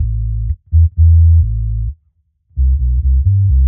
Index of /musicradar/dub-designer-samples/130bpm/Bass
DD_JBass_130_A.wav